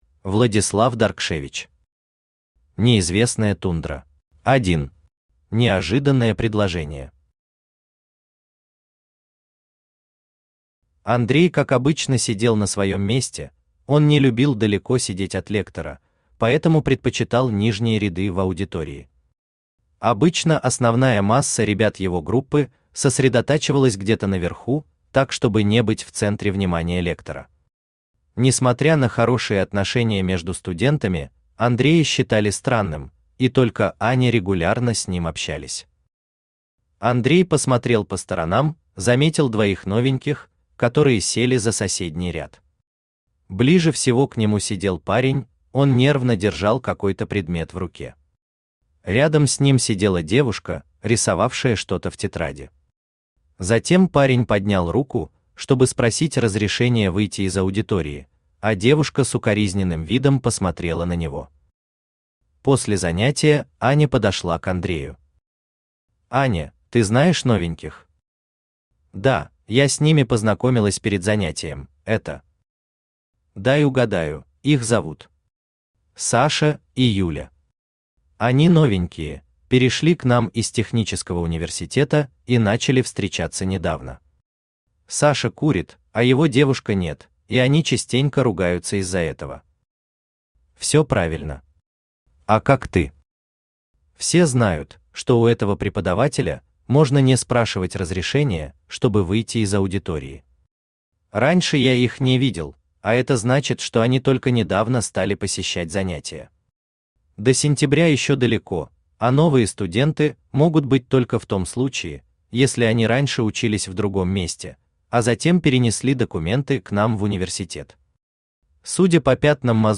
Аудиокнига Неизвестная тундра | Библиотека аудиокниг
Aудиокнига Неизвестная тундра Автор Владислав Даркшевич Читает аудиокнигу Авточтец ЛитРес.